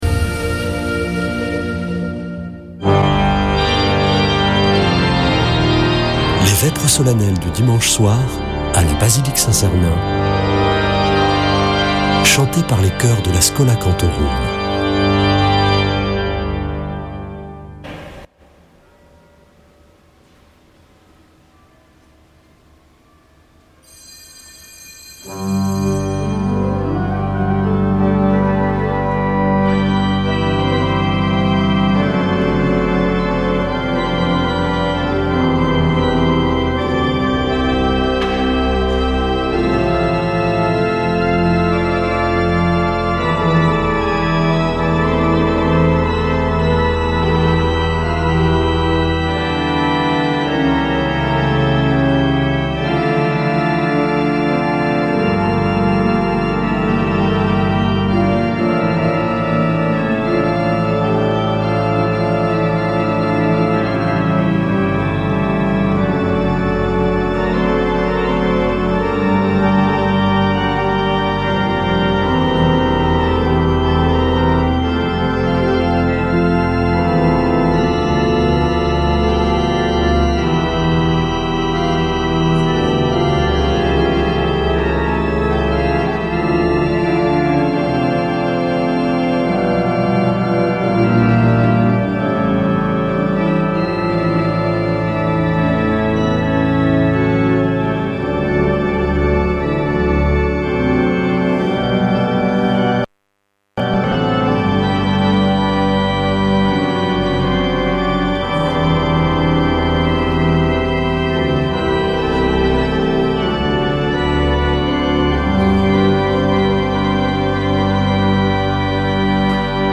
Vêpres de Saint Sernin du 04 mai